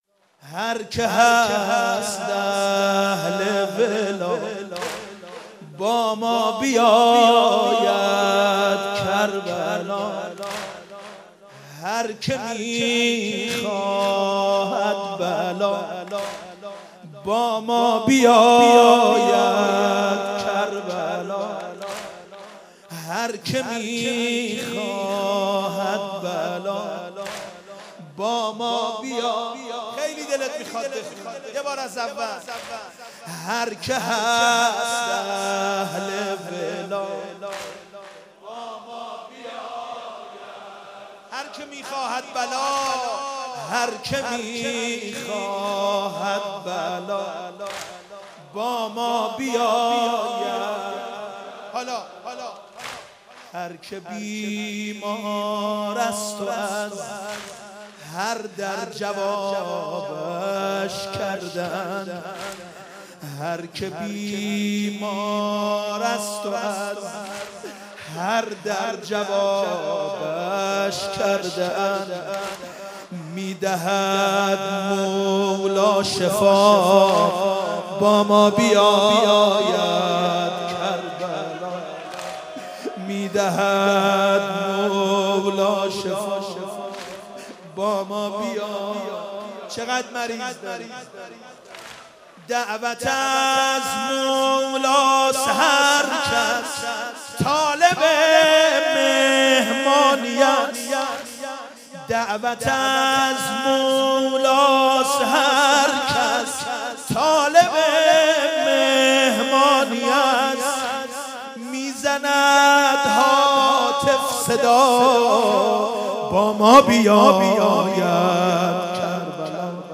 محرم 95